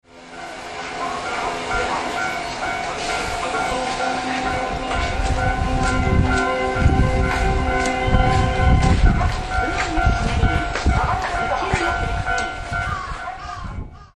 周辺を配慮してか、メロディーの音量が非常に小さいです。
なお、2005年12月に自動放送の言い回しを変更、声が変更されました。
Cielo Estrellado さらに音量小さめ